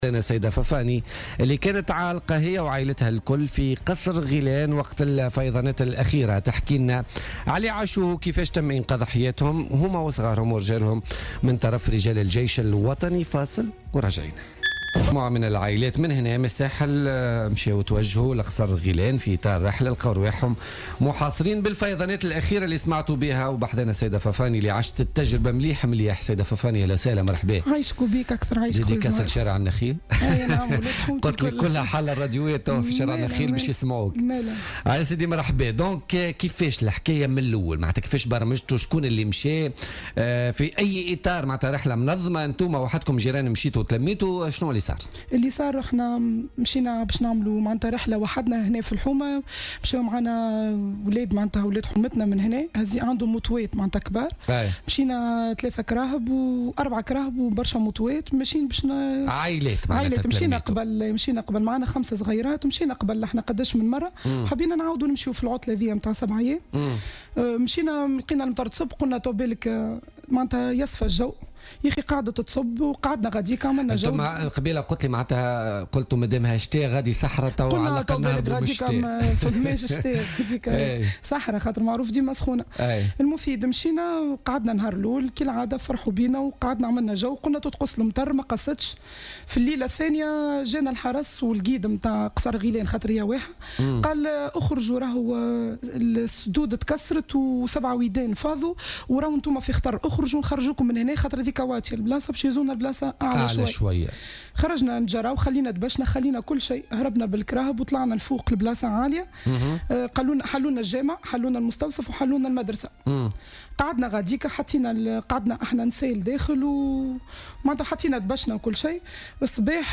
وقالت في شهادتها في برنامج بوليتيكا، إنها كانت ضمن مجموعة تتكون من 18 شخصا زارت مؤخّرا منطقة قصر غيلان السياحية من ولاية قبلي عندما داهمتهم الفيضانات الأخيرة إلا أن من حسن حظهم سارعت قوات الجيش الوطني والحرس الوطني بإغاثتهم بحضور والي الجهة. وقالت إن القوات المسلّحة أظهرت مهنية فائقة في التعامل مع هذا الظرف الطارئ بالإضافة إلى انسانية عالية، بحسب تعبيرها مشيرة إلى كرم أهل الجنوب عموما، بحسب التجربة التي عاشتها مع أسرتها.